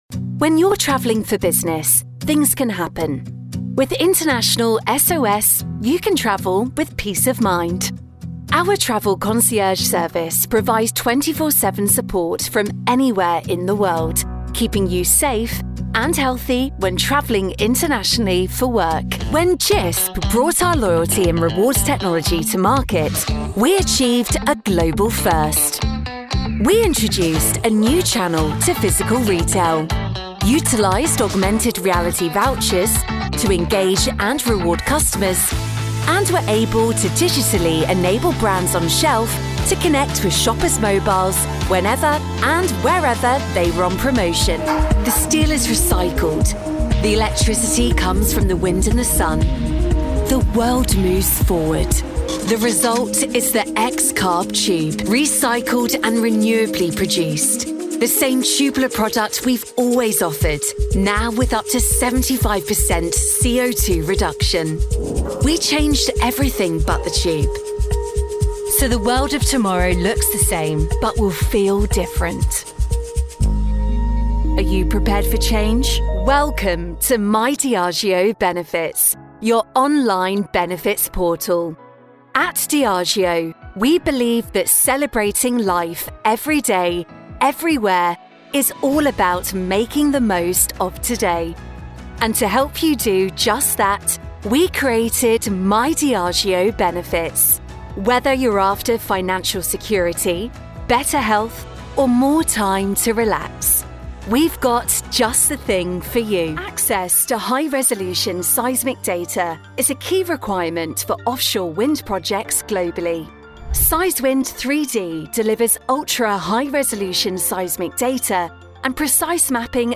Vídeos Corporativos
Sennheiser MK4
Jovem adulto
Mezzo-soprano